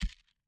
UOP1_Project / Assets / Audio / SFX / Characters / Actions / PigChef / JumpLanding.wav
JumpLanding.wav